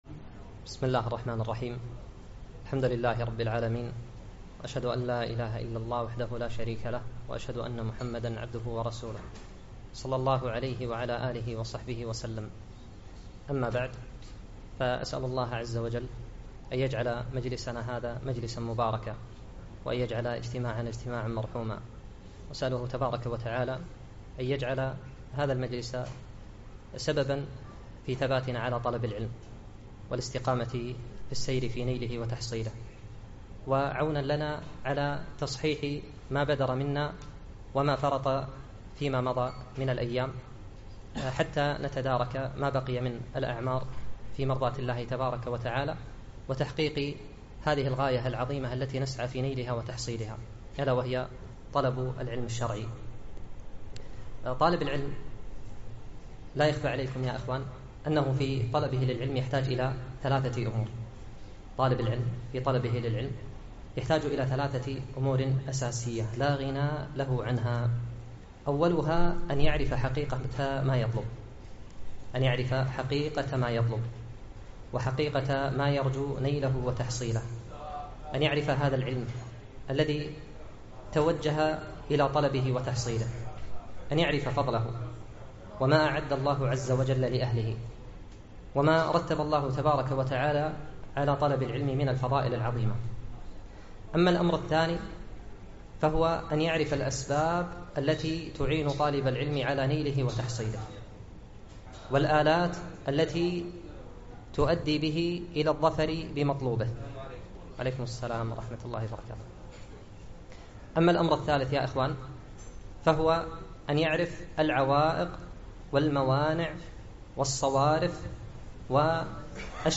محاضرة - الشتات العلمي أسبابه وطرق علاجه